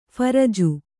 ♪ pharaju